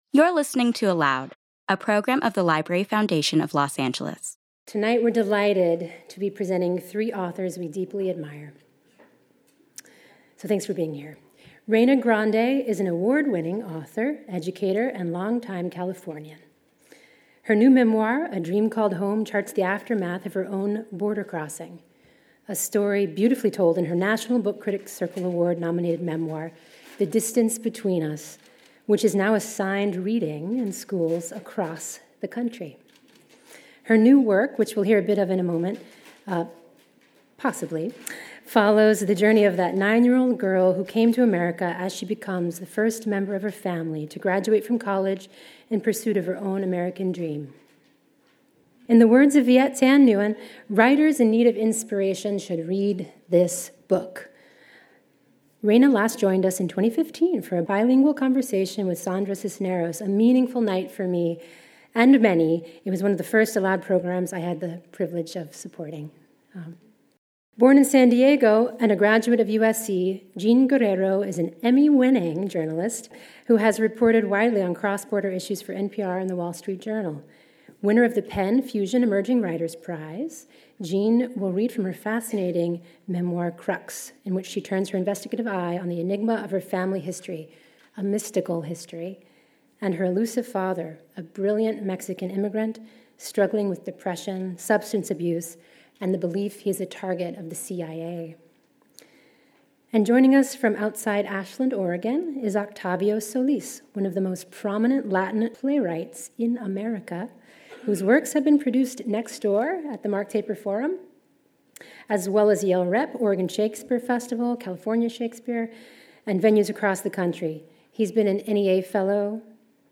Reyna Grande, Jean Guerrero, Octavio Solis Reading and Conversation Tuesday, November 13, 2018 01:20:03 ALOUD Listen: play pause stop / stories-life-lived-along-border.mp3 Listen Download this episode